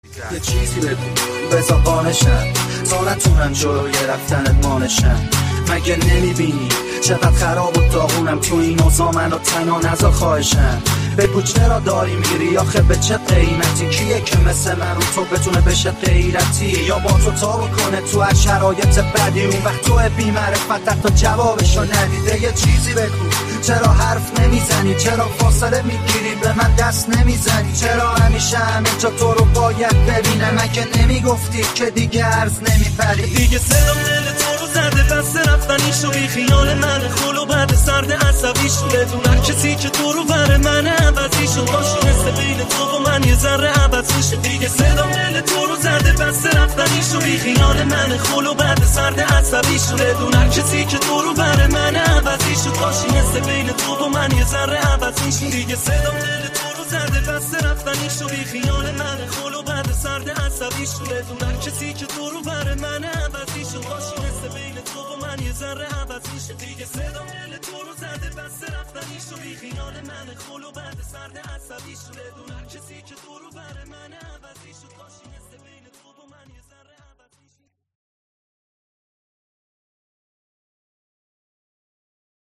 نسخه ریمیکس